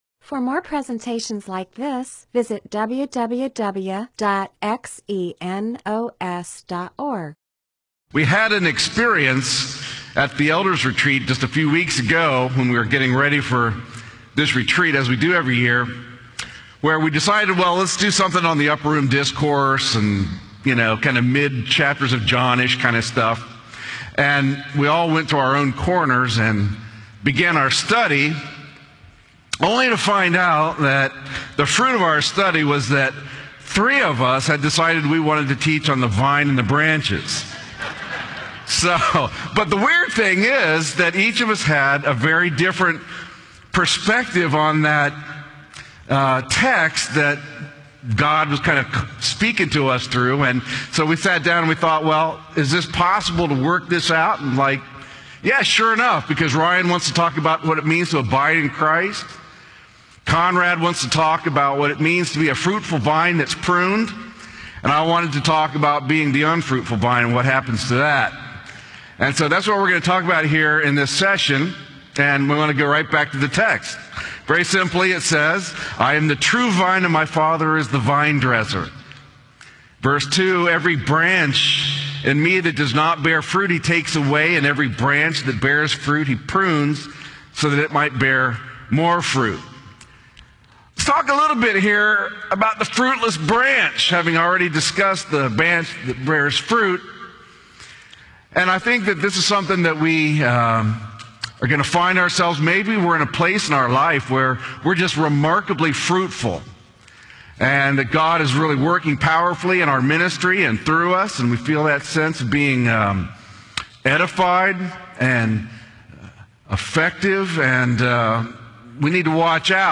MP4/M4A audio recording of a Bible teaching/sermon/presentation about John 15:1-2.